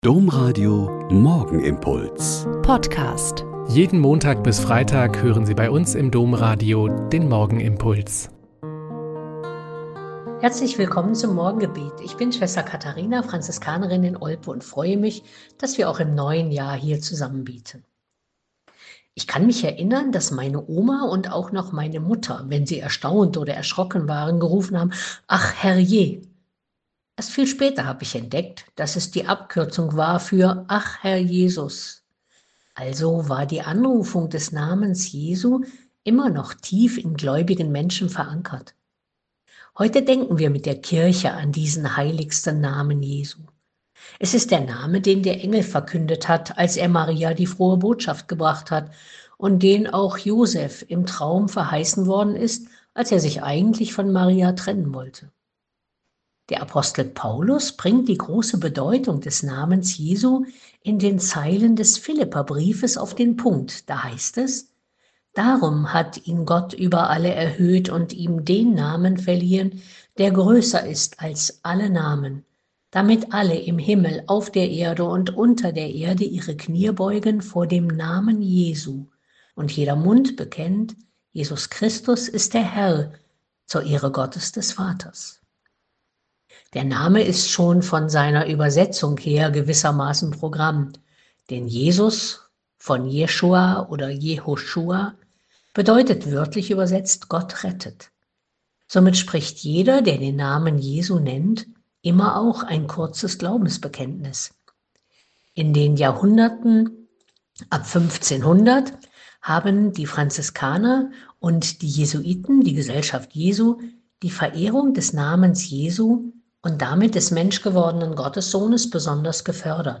Joh 1,29-34 - Gespräch mit Tanja Kinkel - 03.01.2025